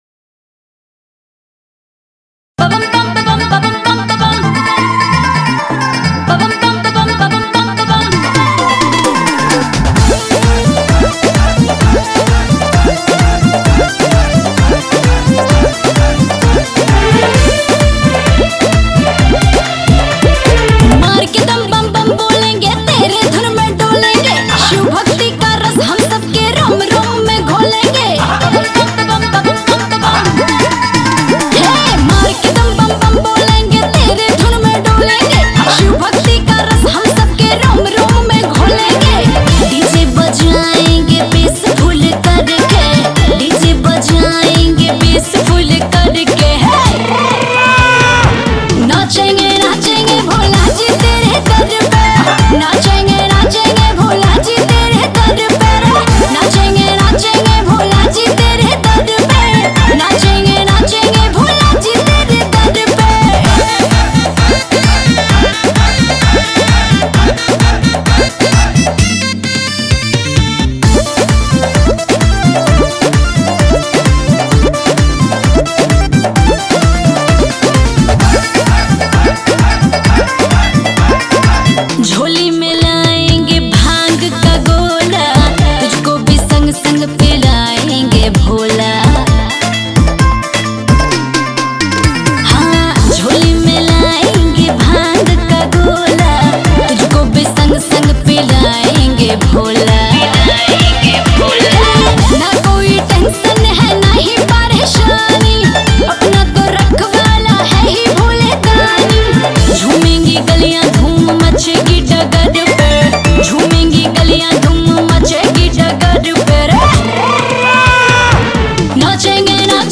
dj shiv bhajan